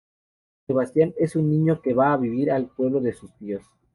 Se‧bas‧tián
/sebasˈtjan/